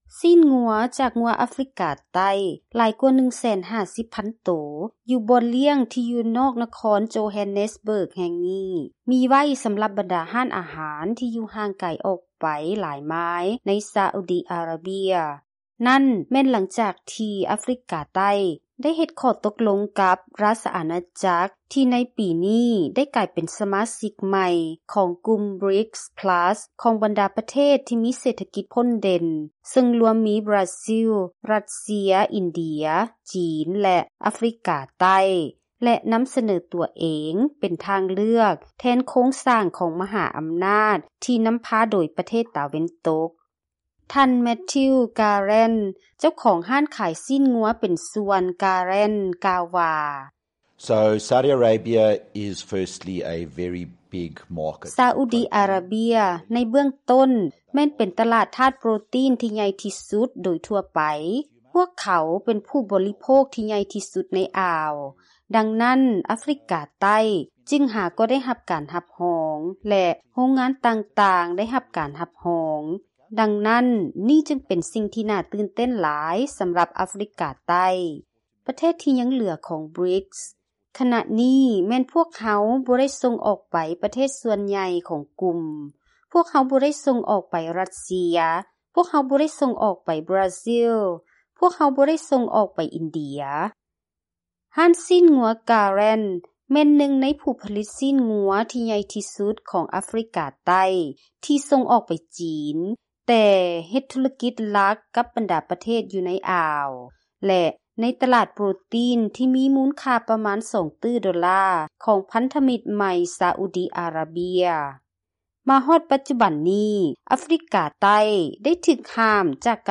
through Zoom: